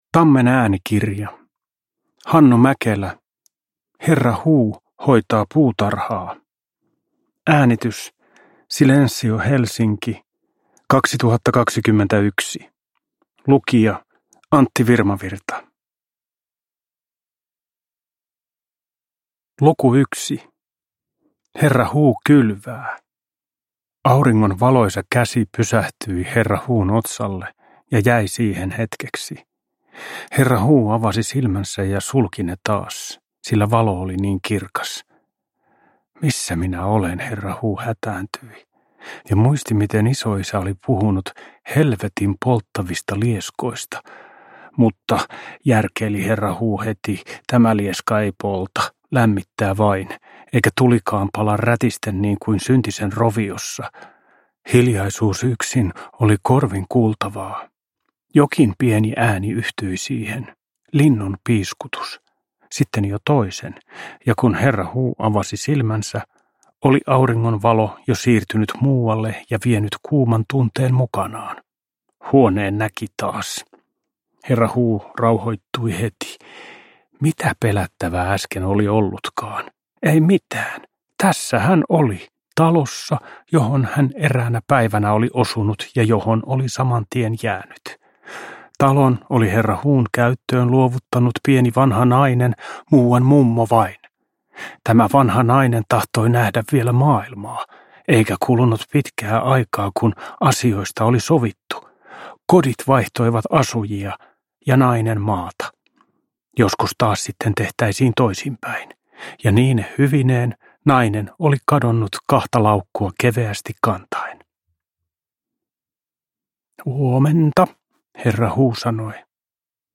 Herra Huu hoitaa puutarhaa – Ljudbok